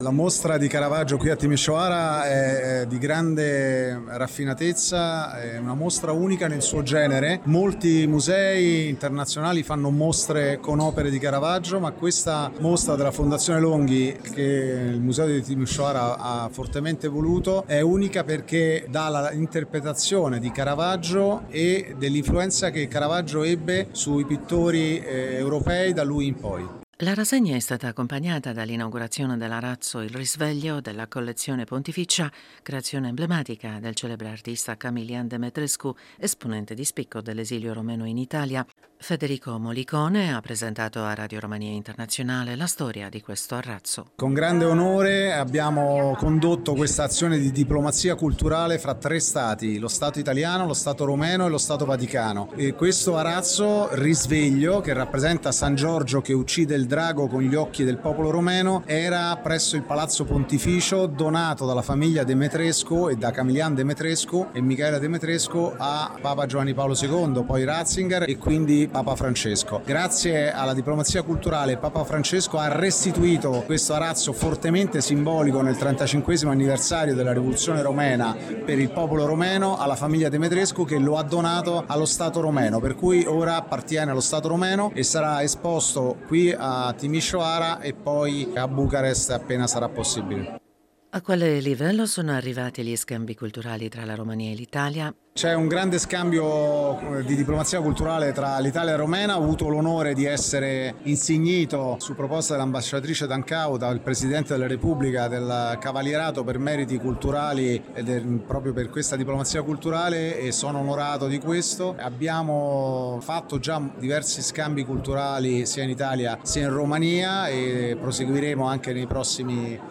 Presente all’inaugurazione della mostra, il presidente della Commissione Cultura, Scienza e Istruzione della Camera dei Deputati, Federico Mollicone, ha parlato dell’importanza della mostra dedicata a Caravaggio e ai suoi discepoli a Timişoara.